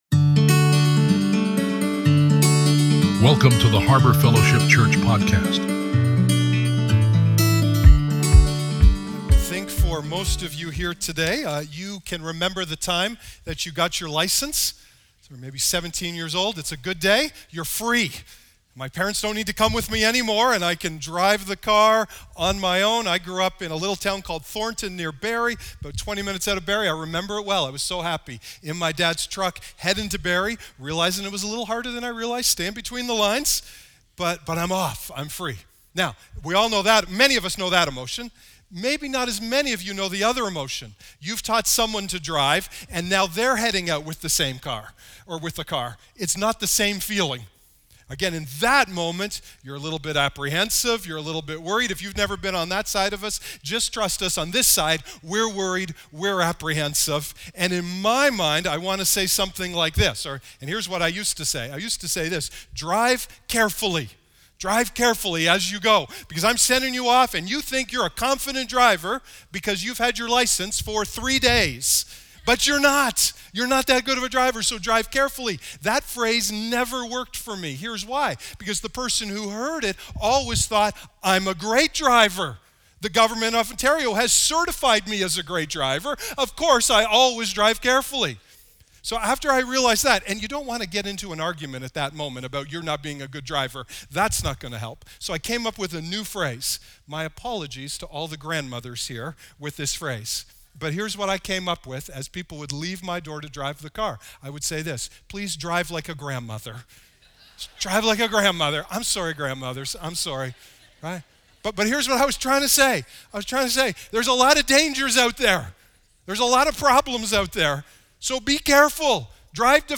Missed one of our Sunday sermons?